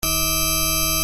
Ponadto dźwięki te posiadają także tę samą wysokość.